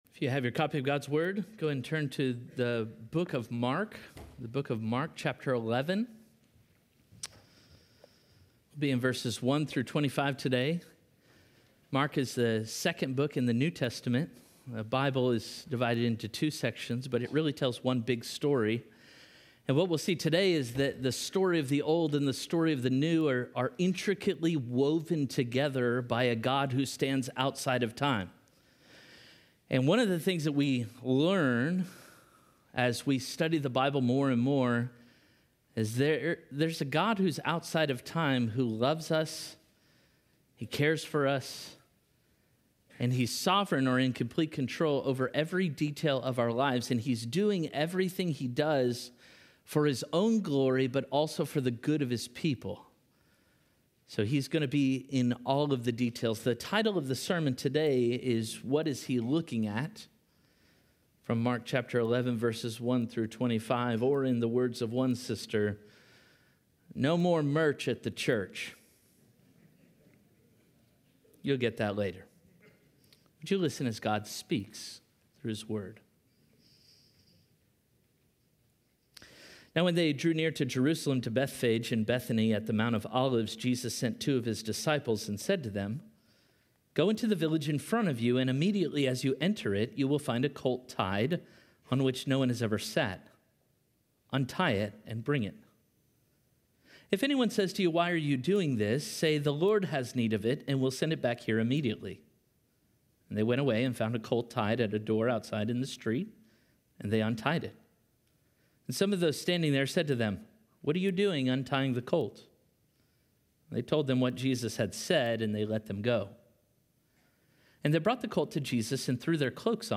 Parkway Sermons What Is He Looking At?